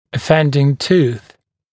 [ə’fendɪŋ tuːθ (tiːθ)][э’фэндин ту:с (ти:с)]зуб (зубы), являющийся причиной какого-либо нарушения